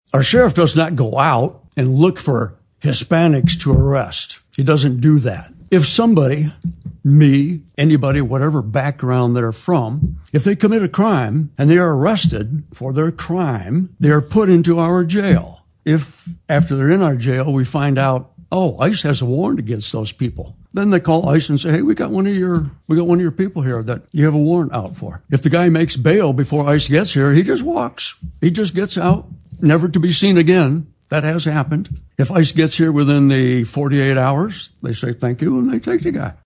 Jackson, Mich. (WKHM) — The Jackson County Board of Commissioners meeting on Tuesday night featured a packed room and multiple hours of public comment regarding the Sheriff’s Office’s (JCSO) 287(g) Agreement with ICE.
Following public comment, Commissioner Tony Bair defended the 287(g) Agreement, saying that the JCSO does not actively seek out individual citizens to arrest.